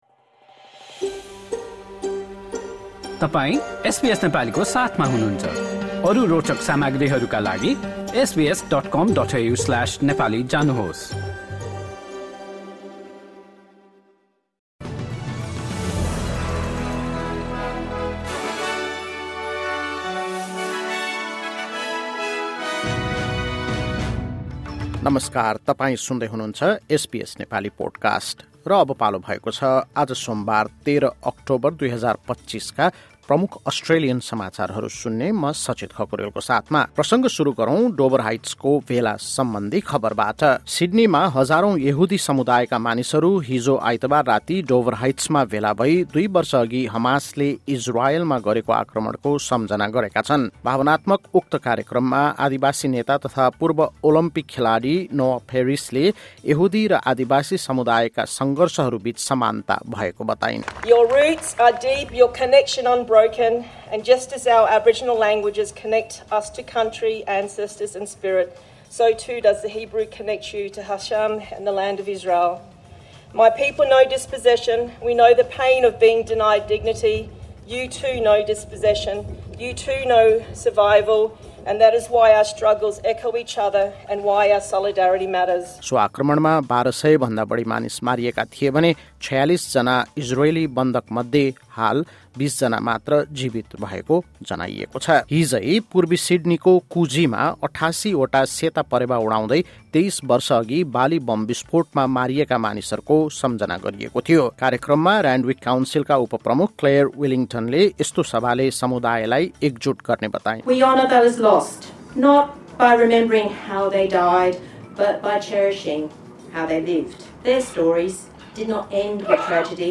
SBS Nepali Australian News Headlines: Monday, 13 October 2025